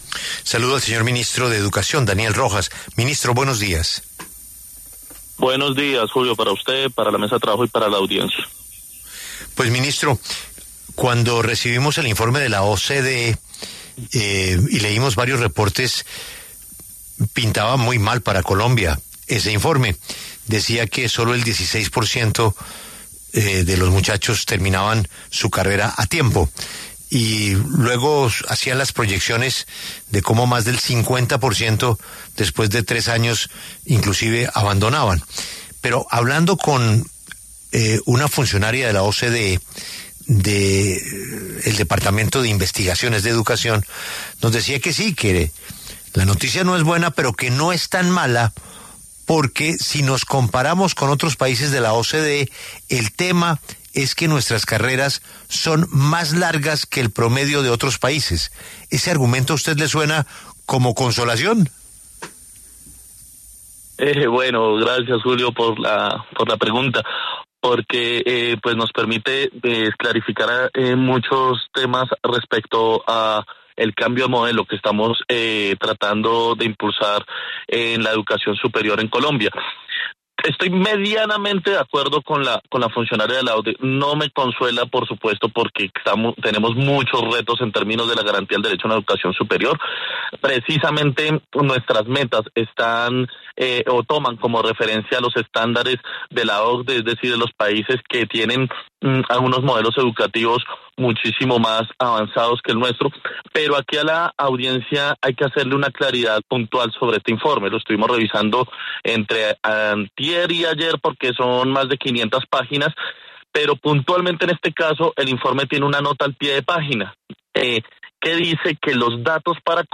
Ante los bajos indicadores revelados por la OCDE en graduación y permanencia universitaria, el ministro de Educación, Daniel Rojas, defendió en La W el modelo de gratuidad en la universidad pública y lanzó críticas directas al esquema de créditos del Icetex.